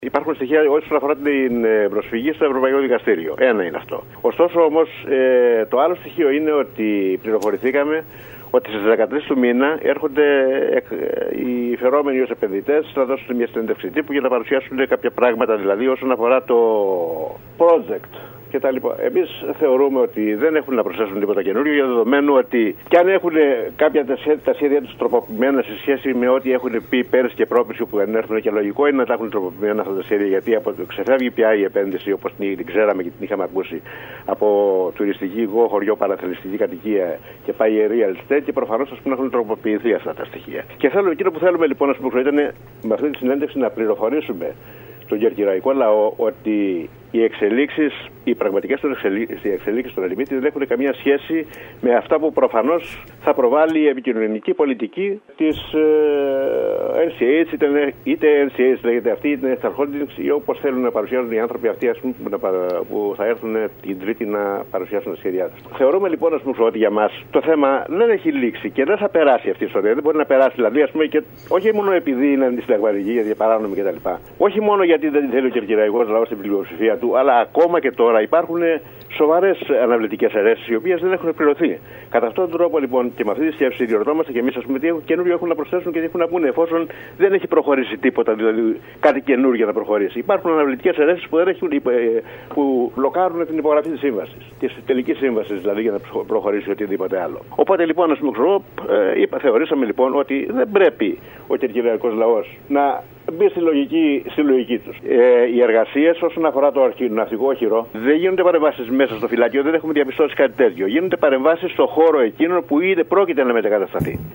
Μιλώντας σήμερα στην ΕΡΤ Κέρκυρας